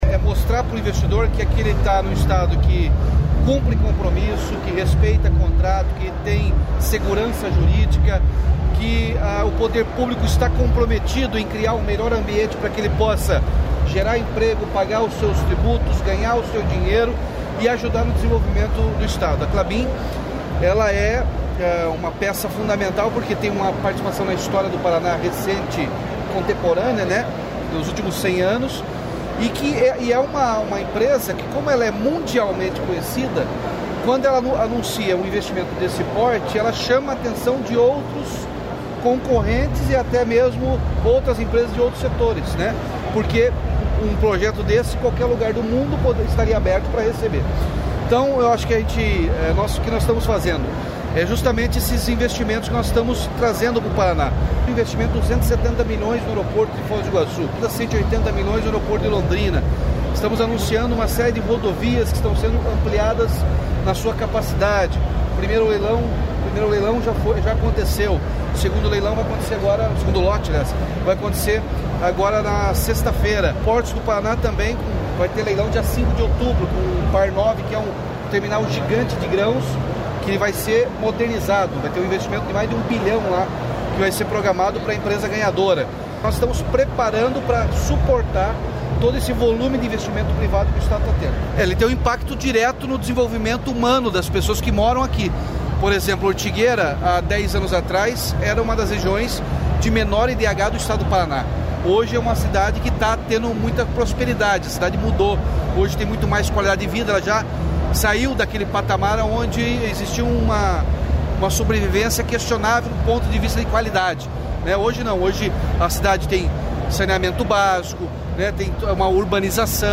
Sonora do governador Ratinho Junior sobre a inauguração da unidade Puma II da Klabin